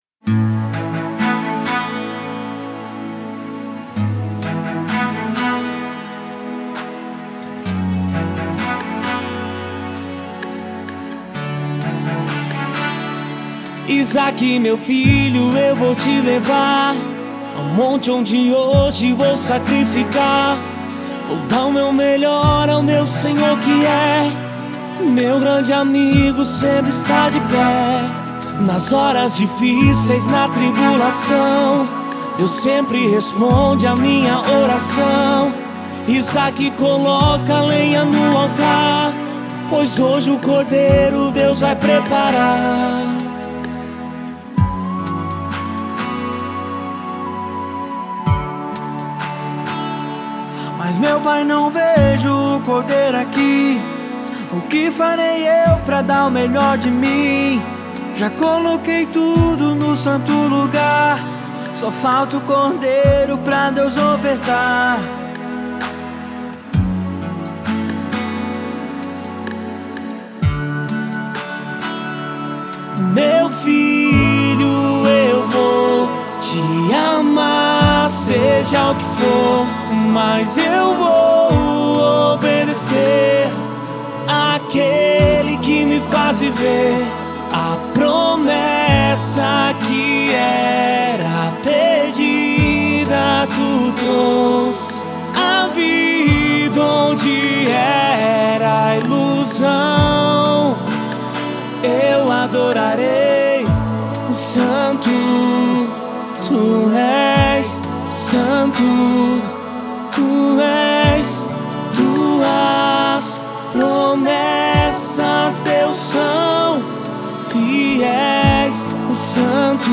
dupla sertaneja